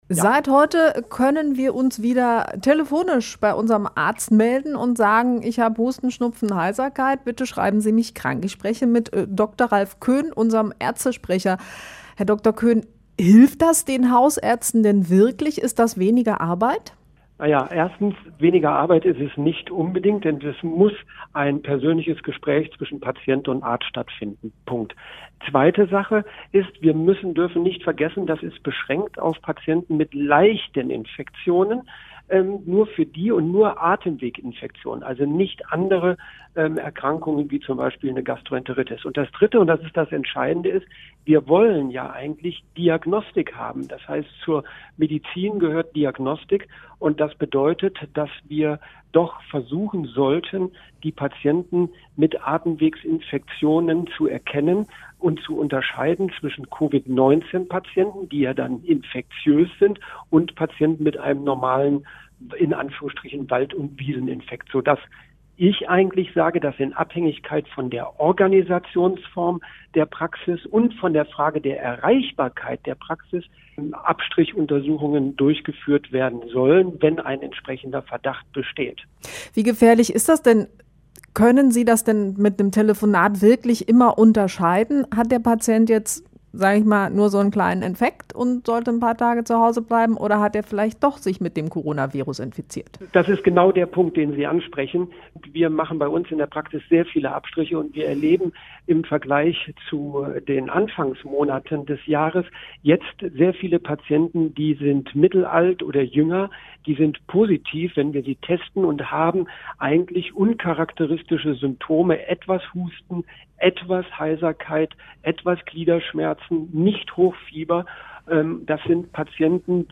int-arzt-krankschreiben-lang.mp3